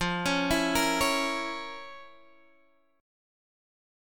FM7sus4#5 chord